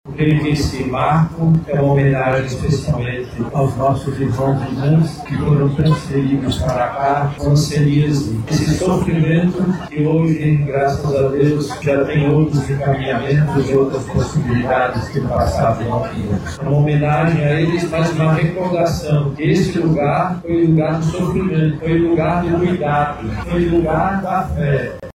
Durante a inauguração do monumento, o Cardeal da Amazônia e Arcebispo Metropolitano de Manaus, Dom Leonardo Steiner, recordou que o bairro Colônia Antônio Aleixo, no passado, acolheu pessoas em situação de extrema vulnerabilidade.